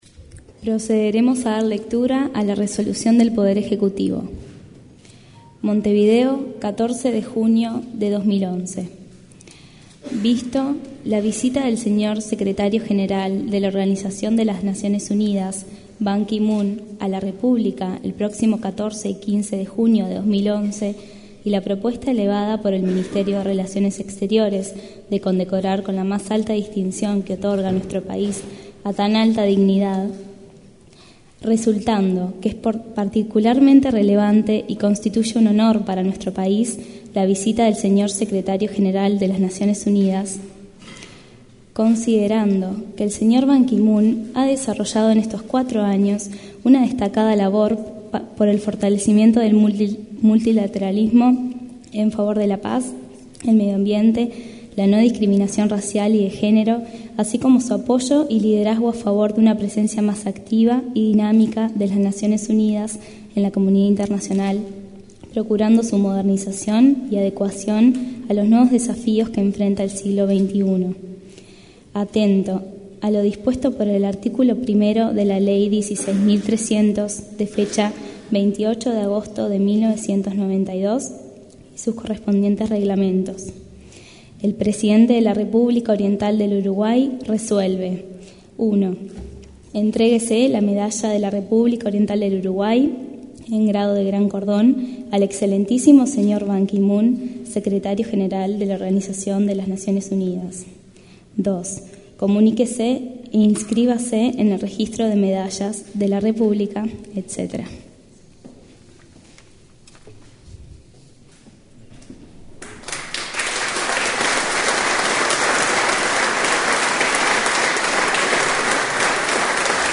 Ver Fotograf�as Escuchar conferencia Ver video